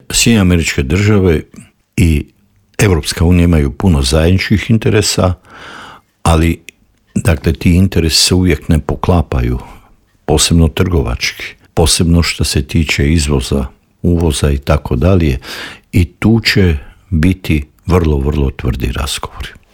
ZAGREB - Dinamična politička zbivanja u svijetu tema su i novog Intervjua Media servisa.